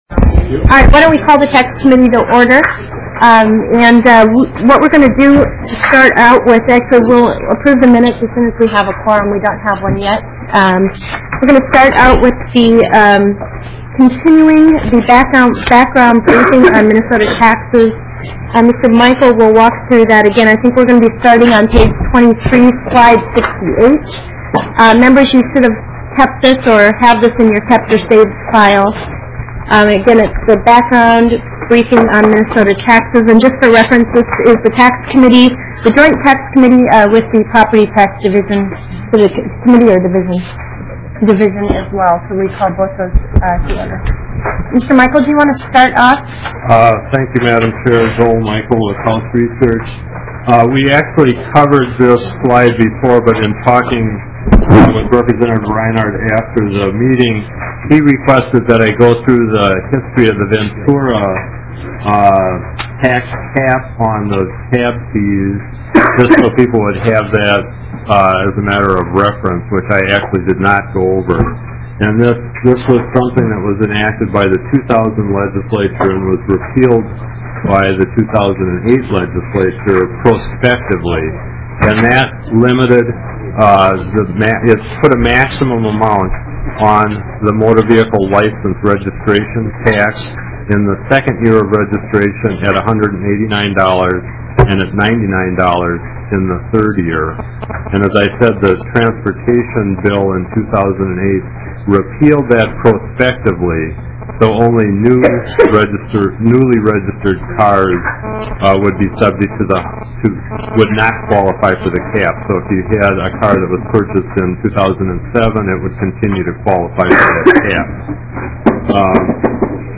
Taxes EIGHTH MEETING 2009-2010 Regular Session - Monday, February 2, 2009 STATE OF MINNESOTA EIGHTH MEETING HOUSE OF REPRESENTATIVES EIGHTY SIXTH-SESSION TAX COMMITTEE MINUTES Representative Laura Brod, Chair of the Committee, called the meeting to order at 2:50 P.M. on February 2nd, 2009 in Room 10 of the State Office Building.